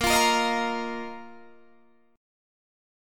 Bbsus4 chord